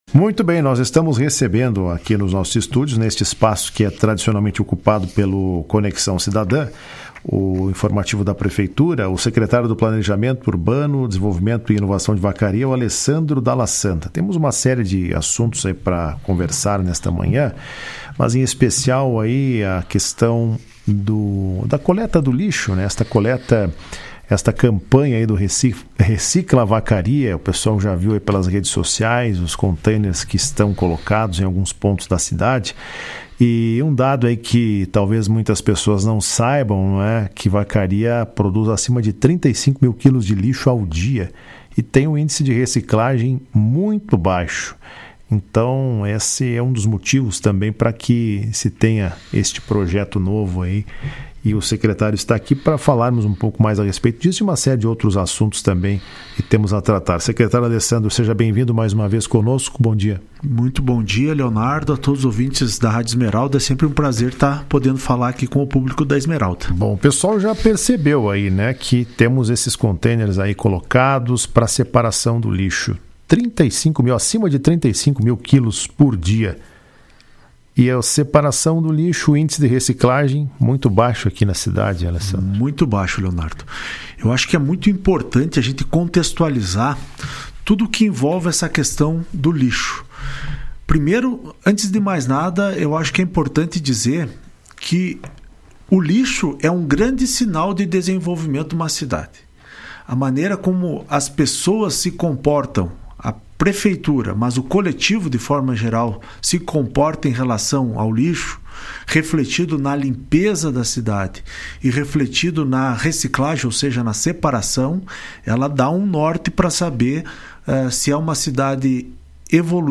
O Secretário Municipal de Planejamento, Urbanismo, Desenvolvimento e Inovação de Vacaria, Alessandro Dalla Santa Andrade, participou do programa Comando Geral desta quarta-feira. Durante a entrevista detalhou sobre a instalação dos containers de lixo na região central da cidade.